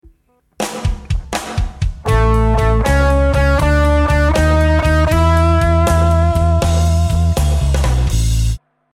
Walking Up Chromatic Lick
This simple but effective lick plays the root note of the I chord then walks up chromatically from the 3rd of the I chord to the root note of the V chord. In the example in the key of A this translates to the root note of A followed by the 3rd note of C# walking up chromatically to E which is the root of the V chord. These notes are double plucked giving them rhythmic movement.
blues_turnaround_lick01.mp3